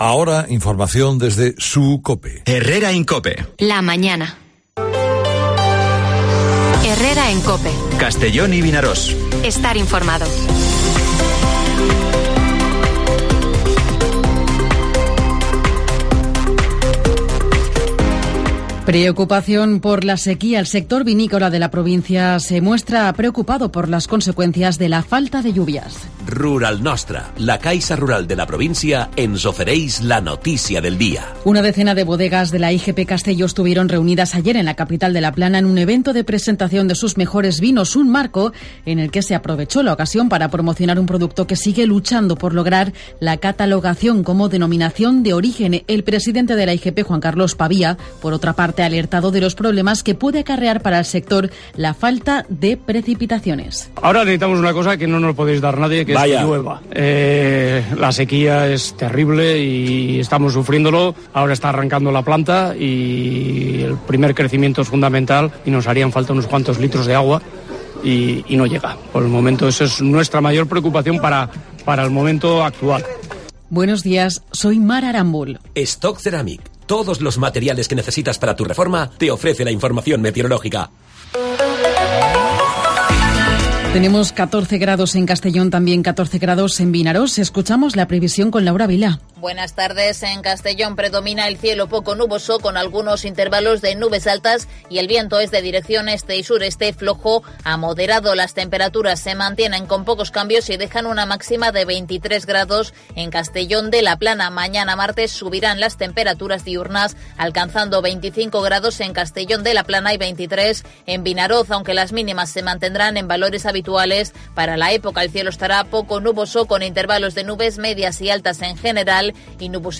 Informativo Herrera en COPE en la provincia de Castellón (25/04/2023)